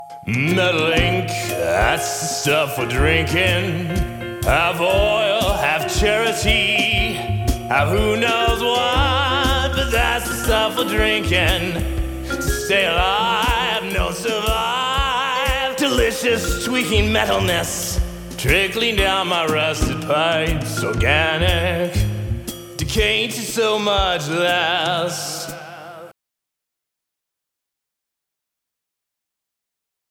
In the full version, indications regarding swing tempo and a jazz rhythm are included.